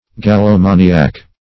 -- Gal`lo*ma"ni*ac , n. [1913 Webster]
gallomaniac.mp3